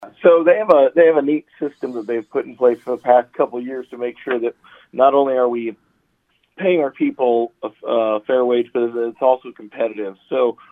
Audubon County Supervisor Chairman Heath Hansen.